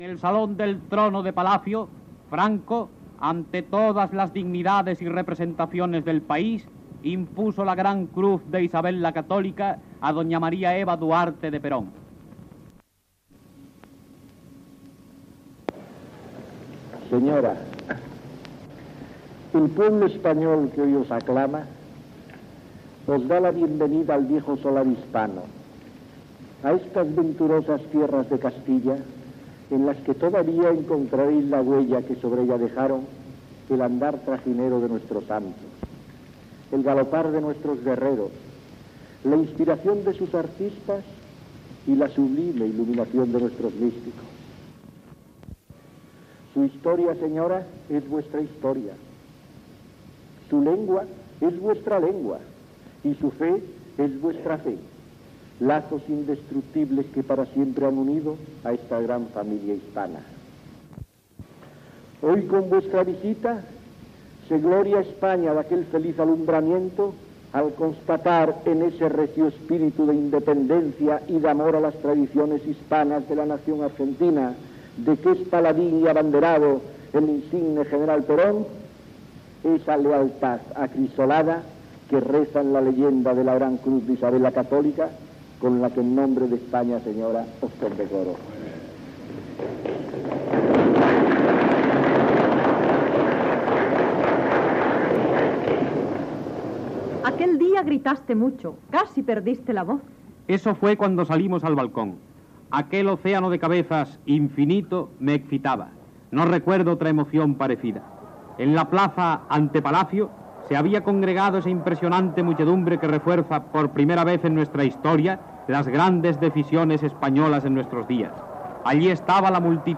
Crònica de la visita de la primera dama argentina, Eva Duarte de Perón, a Espanya. Paraules del "generalísimo" Franco en condecorar-la. Paraules d'Eva Duarte de Perón i Francisco Franco al Palacio de Oriente de Madrid, "Cara al sol", "Arriba España".
Informatiu